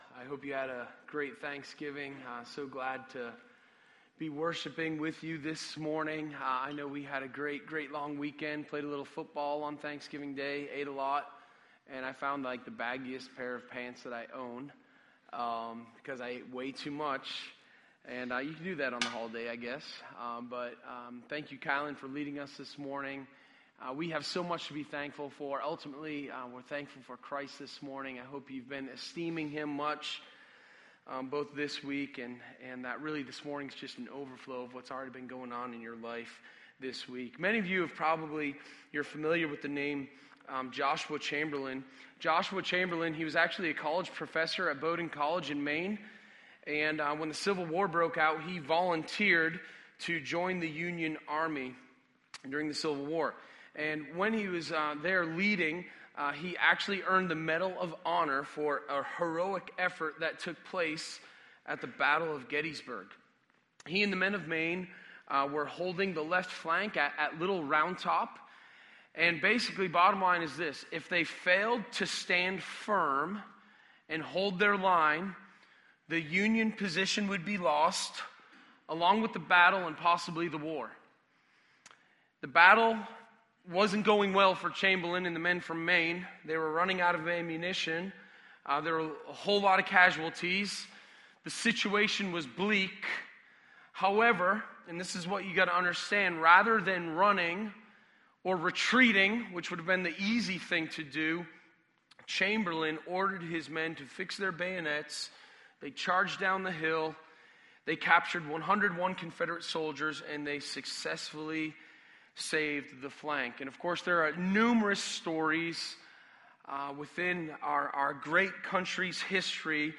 Sermon1127_6LivingtheGoodLife.mp3